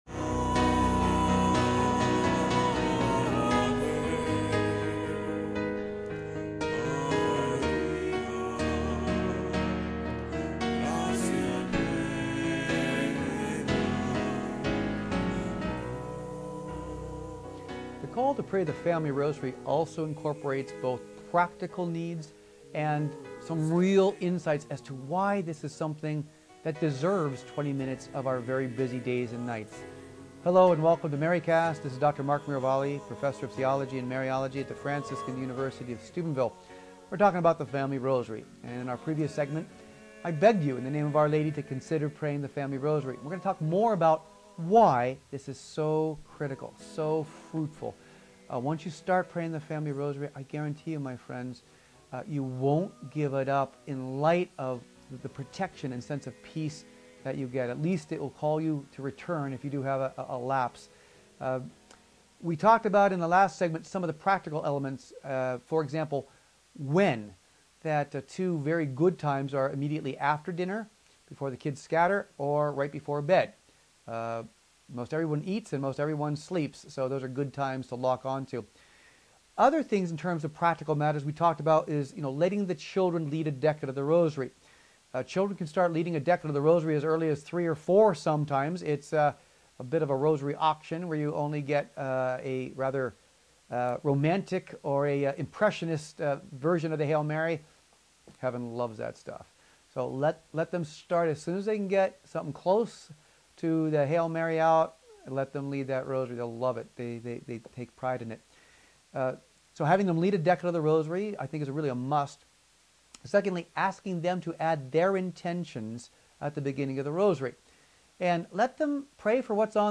Continuing his talk on the Family Rosary